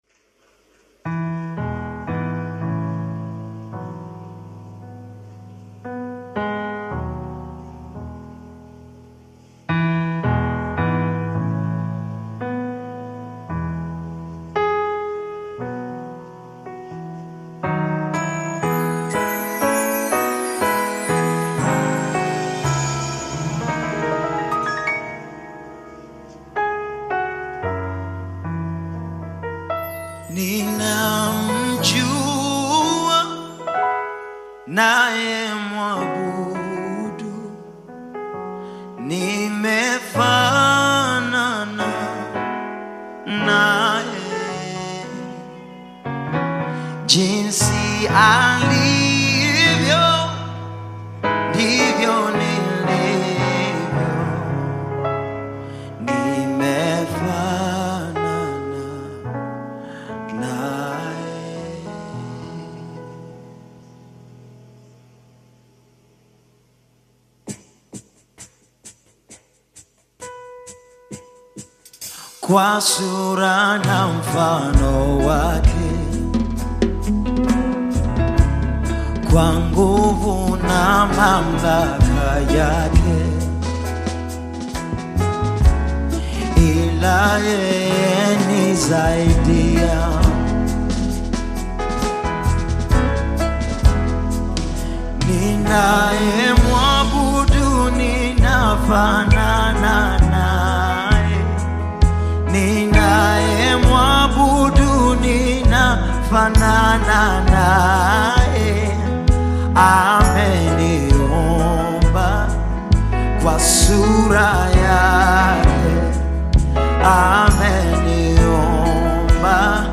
With its catchy melody and deeply meaningful lyrics